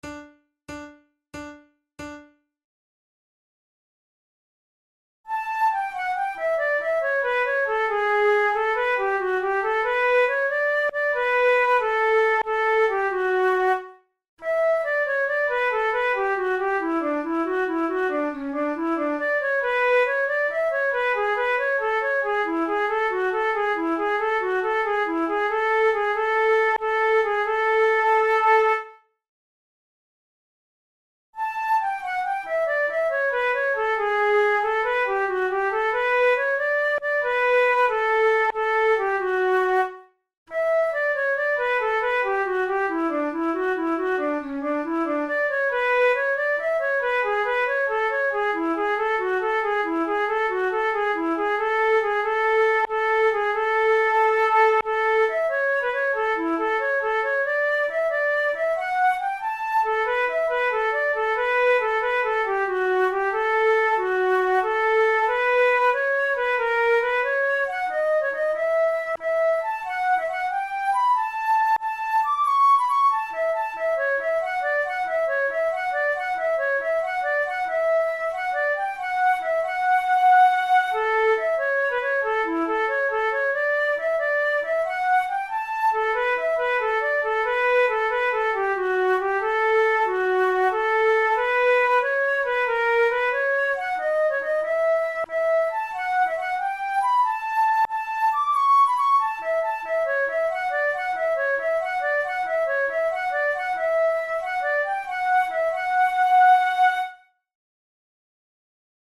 Sheet Music MIDI MP3 Accompaniment: MIDI
Everybody knows Pachelbel's Canon, but the Gigue that originally accompanied it never received the same amount of popularity, even though it is a lively and energetic dance.
Categories: Baroque Jigs Difficulty: intermediate